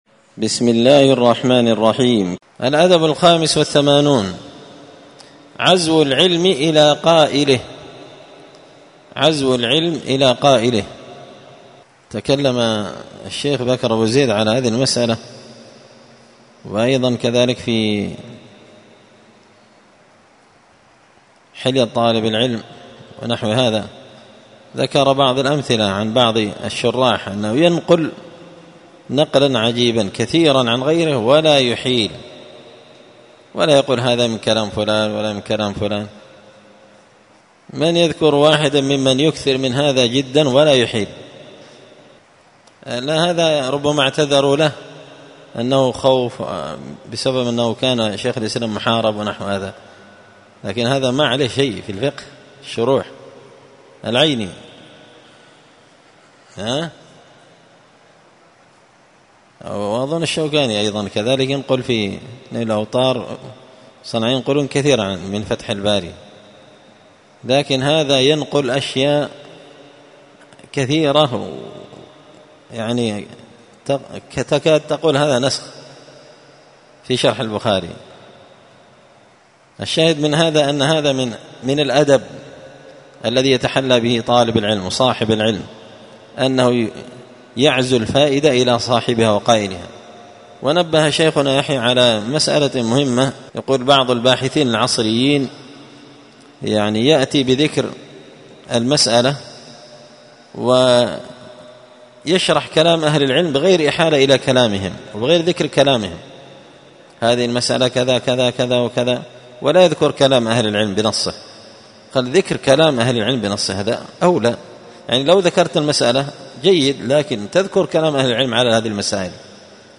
مسجد الفرقان قشن_المهرة_اليمن
الدرس الخامس والتسعون (95) الأدب الخامس والثمانون عزو العلم إلى قائله
الجمعة 30 صفر 1445 هــــ | الدروس، النبذ في آداب طالب العلم، دروس الآداب | شارك بتعليقك | 105 المشاهدات